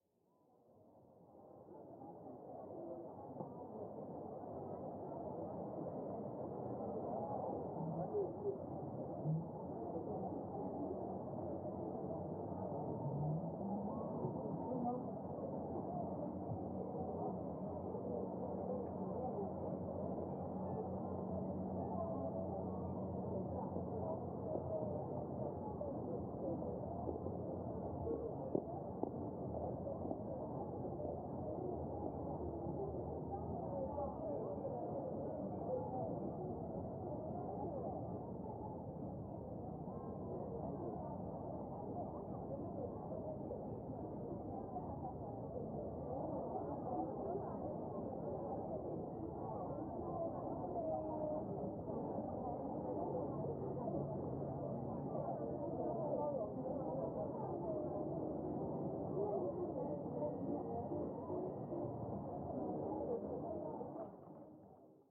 人声背景音.ogg